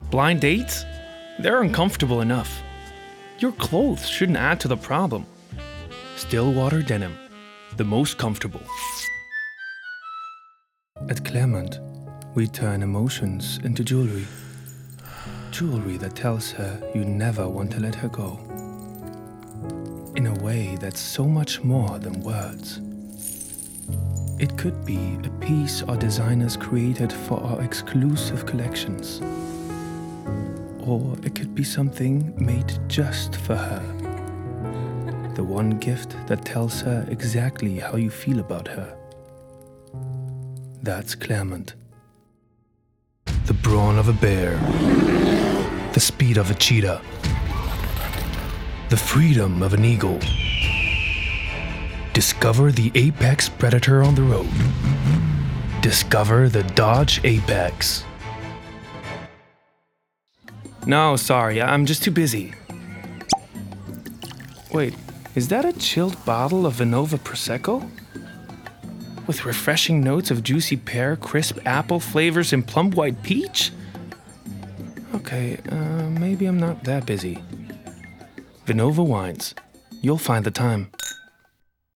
sehr variabel, hell, fein, zart, markant
Mittel minus (25-45)
Werbung Reel Englisch
Commercial (Werbung)